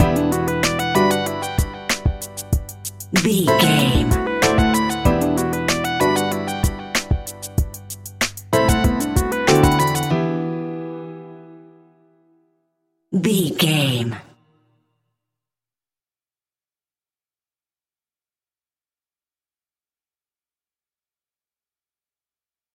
Aeolian/Minor
instrumentals
chilled
laid back
groove
hip hop drums
hip hop synths
piano
hip hop pads